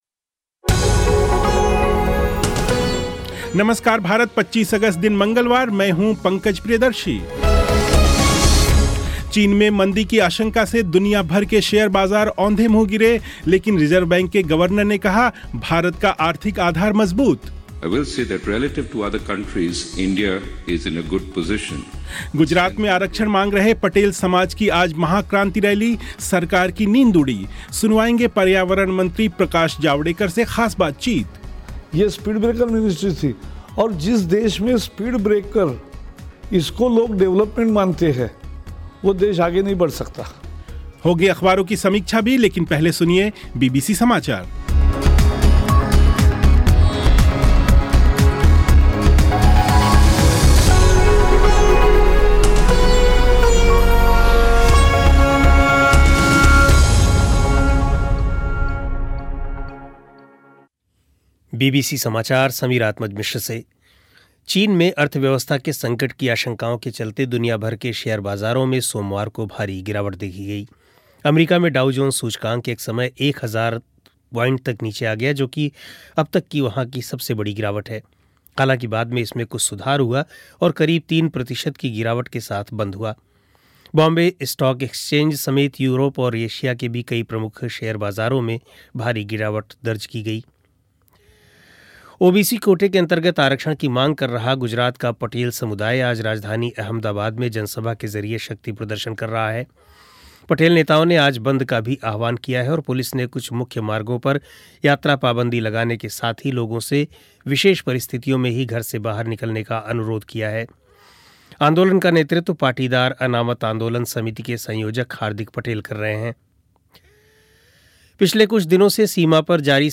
और पर्यावरण मंत्री प्रकाश जावड़ेकर से ख़ास बातचीत.